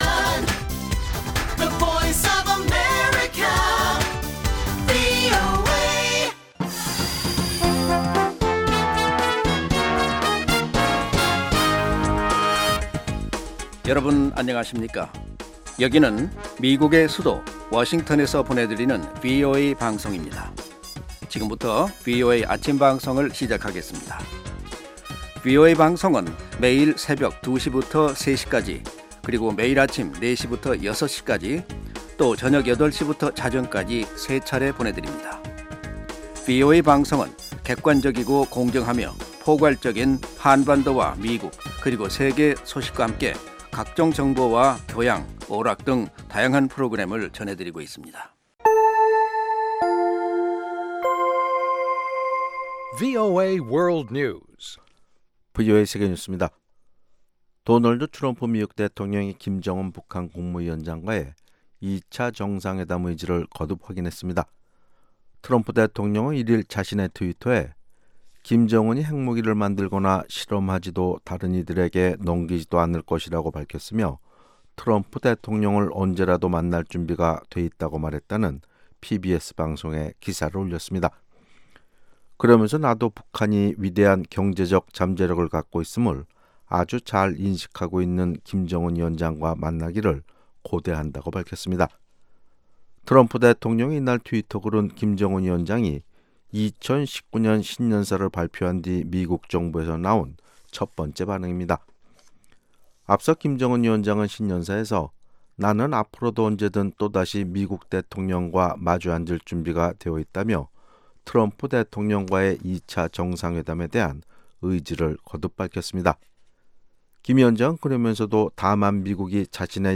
세계 뉴스와 함께 미국의 모든 것을 소개하는 '생방송 여기는 워싱턴입니다', 2019년 1월 3일 아침 방송입니다. ‘지구촌 오늘’은 중국과 타이완은 반드시 통일해야 하고, 이를 위해 무력도 사용할 수 있다고 시진핑 중국 국가주석이 연설했다는 소식, ‘아메리카 나우’에서는 연방 정부 부분 폐쇄가 2일로 12일째를 맞은 가운데 도널드 트럼프 대통령이 연방 의회 지도부를 만날 예정인 이야기를 전해드립니다.